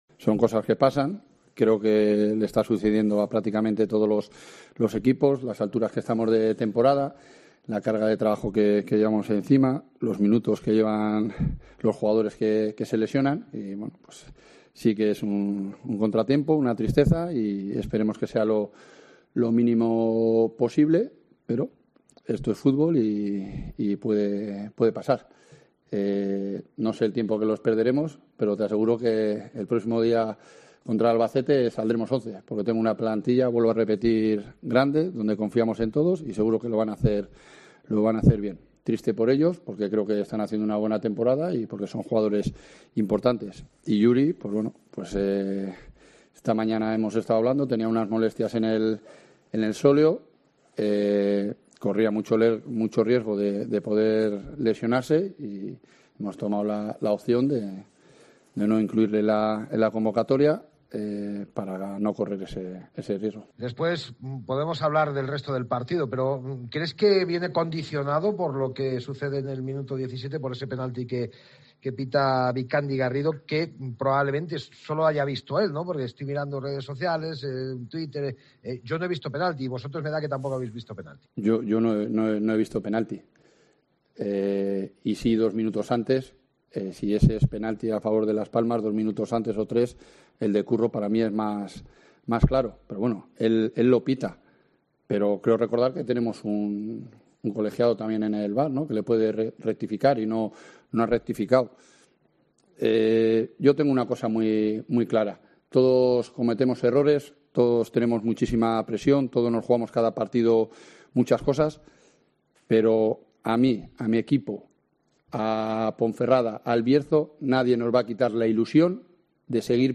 AUDIO: Escucha aquí las declaraciones del entrenador de la Deportiva Ponferradina, Jon Pérez Bolo, y del míster del Las Palmas, Pepe Mel